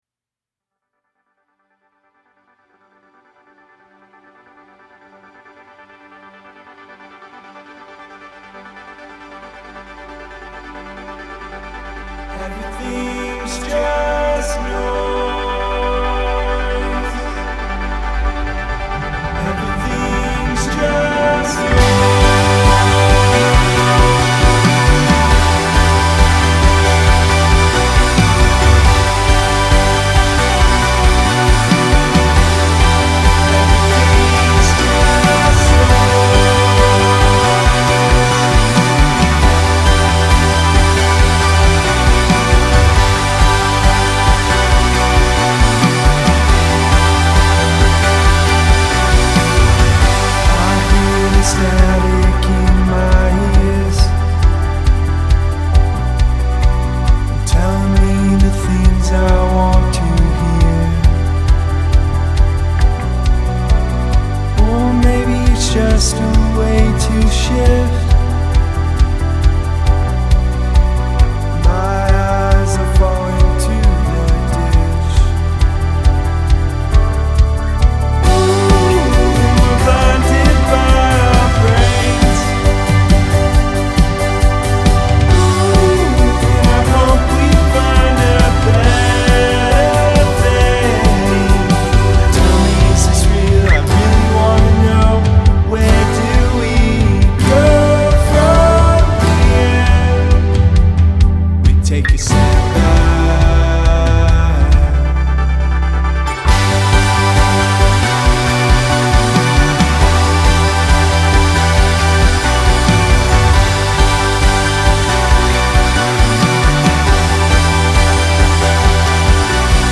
alternative rock/pop